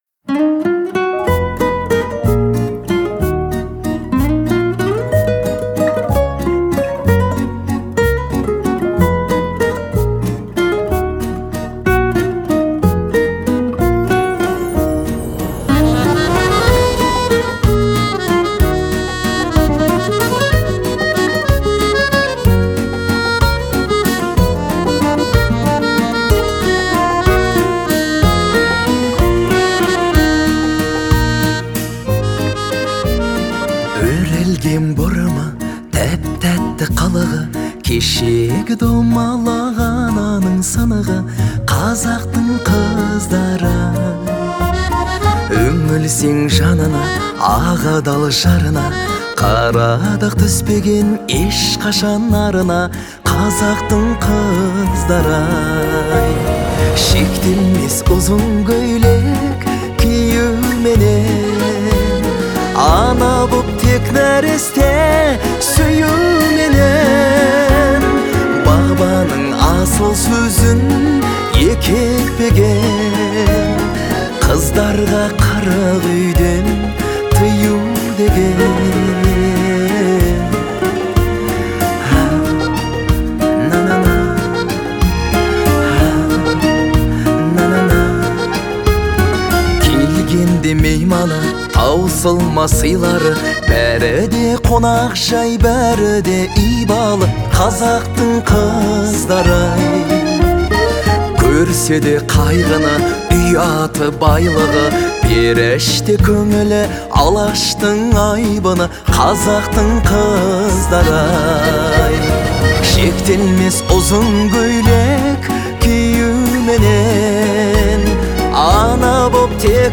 его голос звучит тепло и искренне.